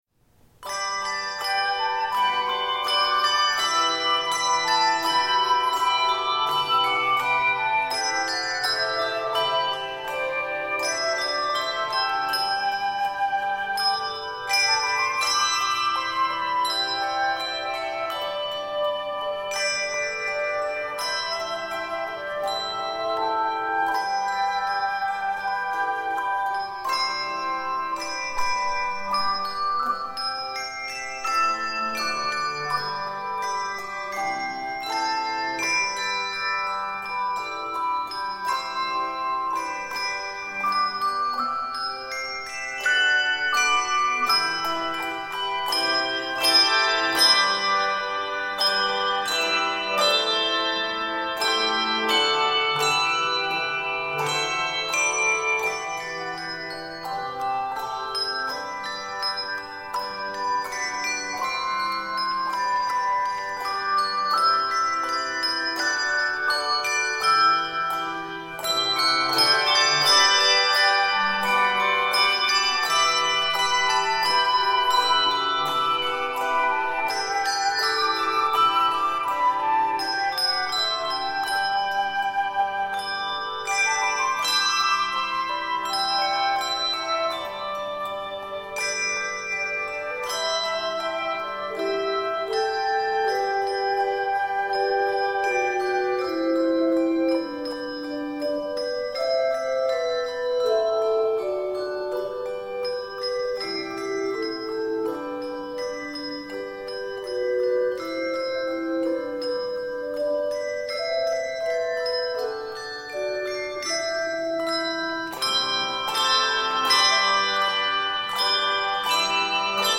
Arranged in G Major